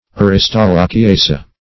aristolochiaceae - definition of aristolochiaceae - synonyms, pronunciation, spelling from Free Dictionary